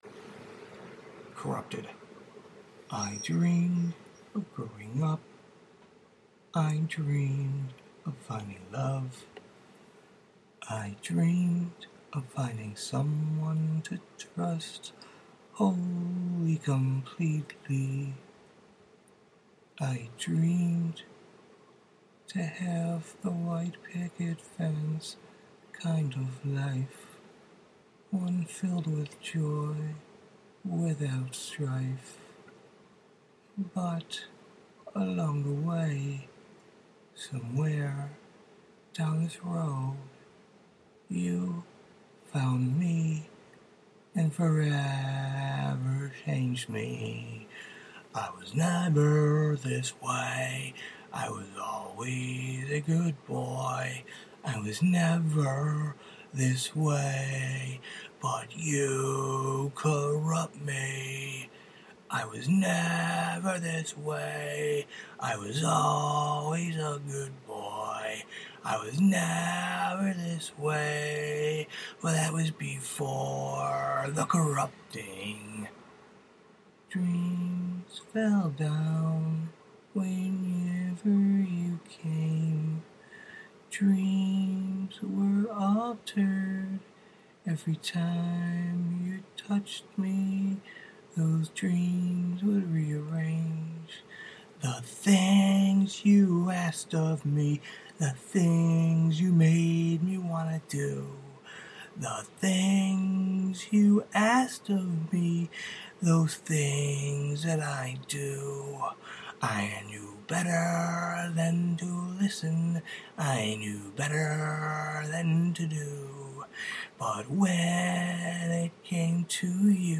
Song, Recording, Singing, Vocals, Lyrics